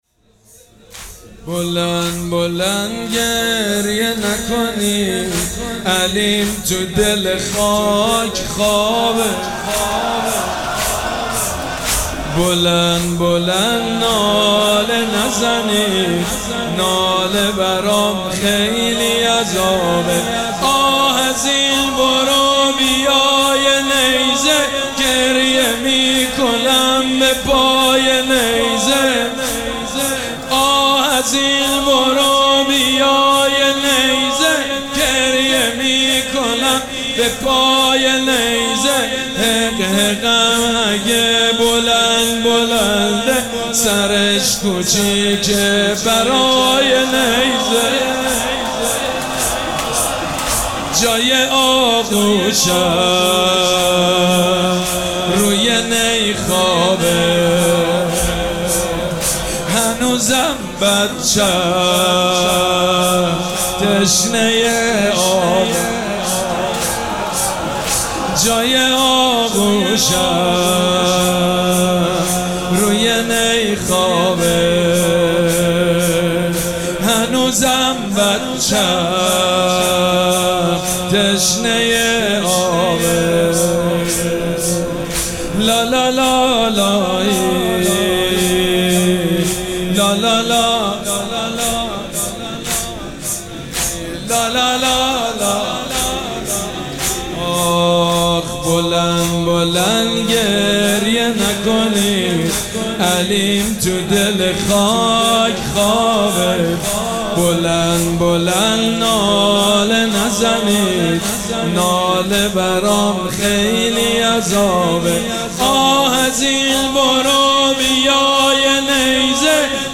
مراسم عزاداری شب هفتم محرم الحرام ۱۴۴۷
مداح
حاج سید مجید بنی فاطمه